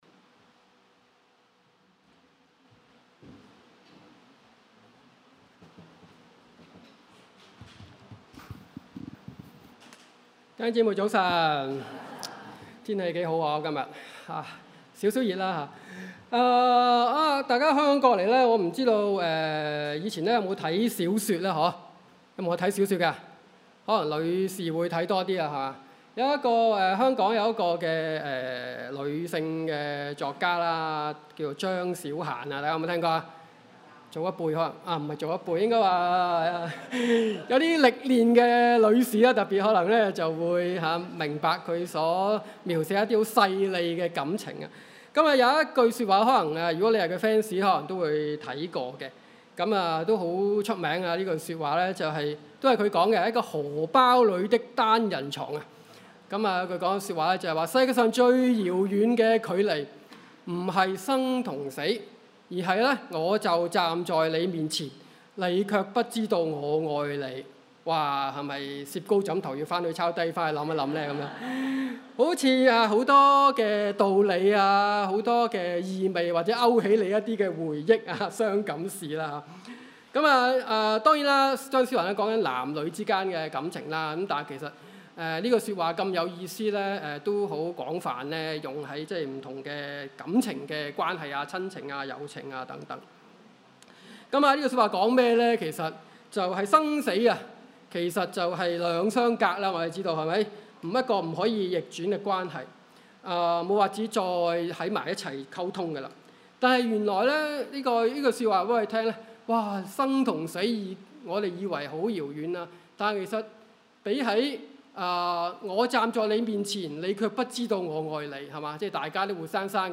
Bible Text: 列王紀下5:1-19 | Preacher